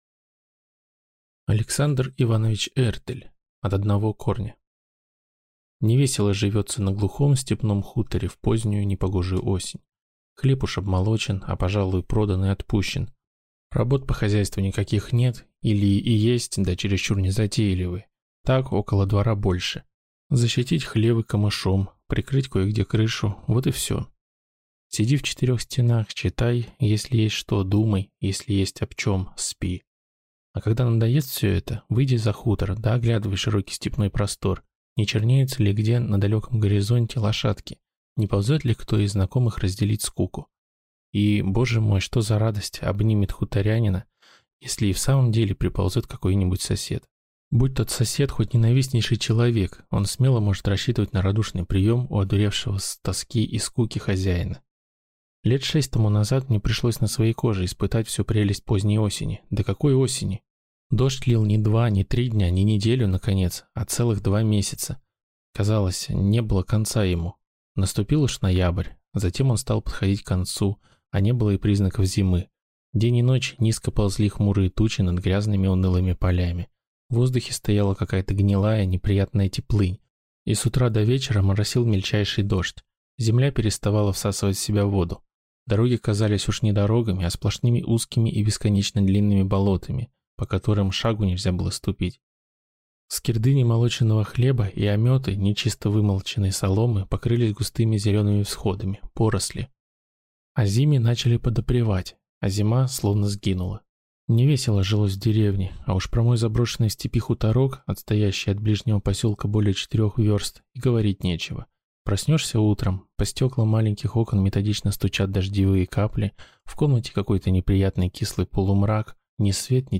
Аудиокнига От одного корня | Библиотека аудиокниг